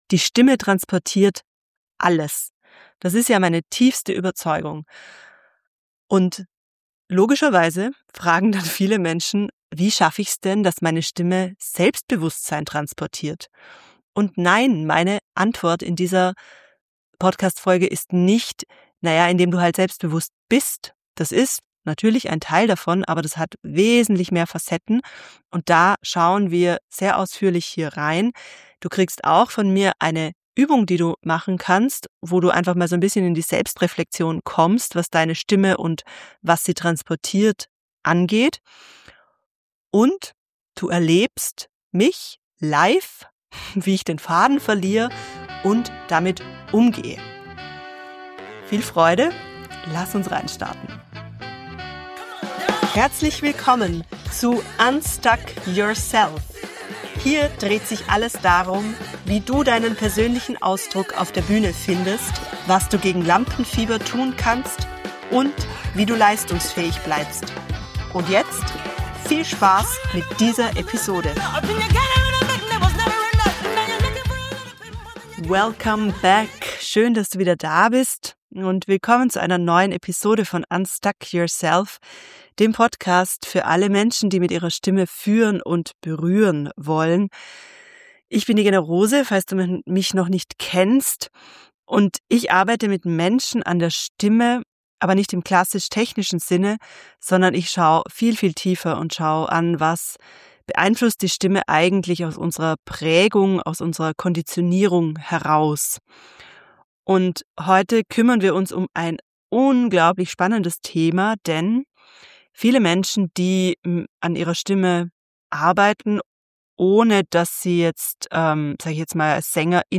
In dieser Episode schauen wir tief rein, was das eigentlich (für dich) bedeutet und wie es gelingt, dass eine Stimme tatsächlich Selbstbewusstsein vermittelt. Außerdem bekommst du eine Übung, deren Aufnahme du mir anschließend für ein persönliches Feedback schicken kannst. Und mitten in der Folge erlebst du eine ungeplante Live-Demonstration meines Verständnisses von Selbst-Bewusst-Sein, als ich kurz den Faden verliere – und es genau so drin lasse.